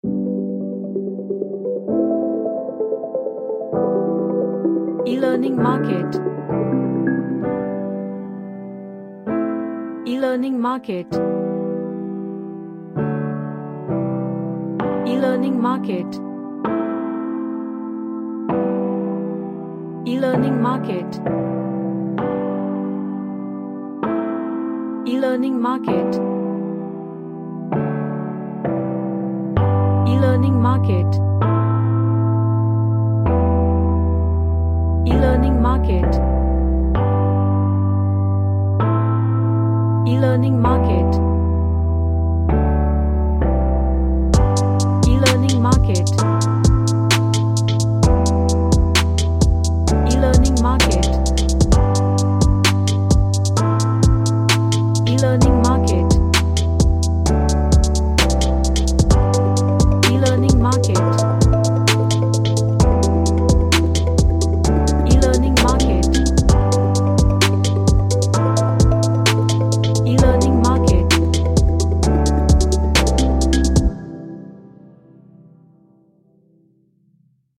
A plucked harmonic synth track
Happy / Cheerful